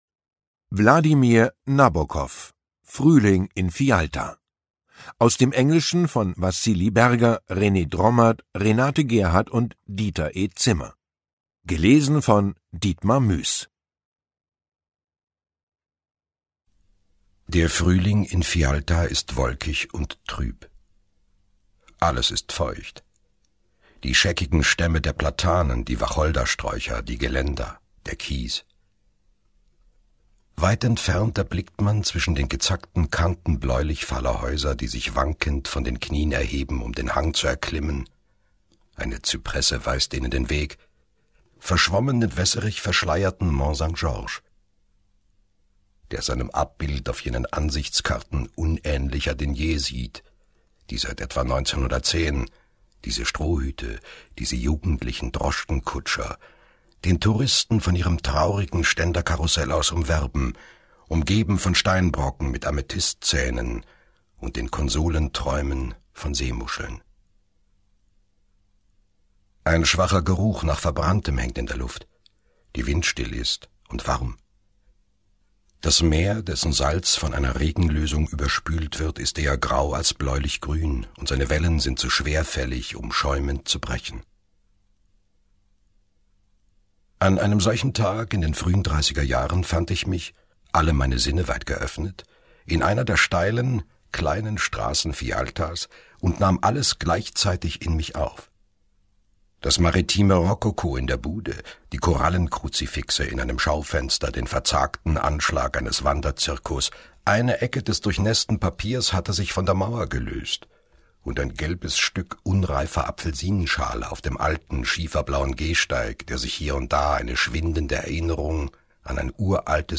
Ungekürzte Lesung mit Dietmar Mues (1 mp3-CD)
Dietmar Mues (Sprecher)